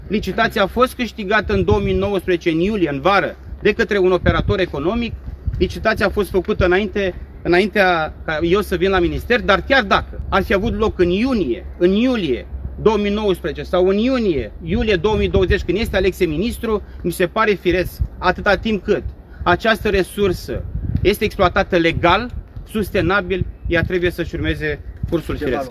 Acuzat că în timpul mandatului său, pădurea Dobrovăţ de lângă Iaşi a fost tăiată ilegală, ministrul Mediului a chemat astăzi presa chiar în pădurea respectivă, unde a ținut o conferintă de presă.